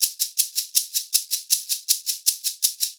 80 SHAK 16.wav